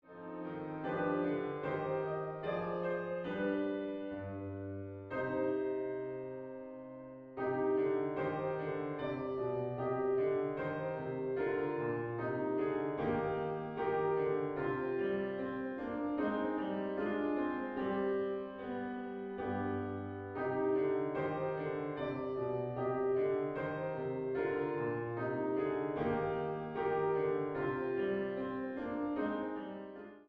Geistiges Lied
Klavier-Sound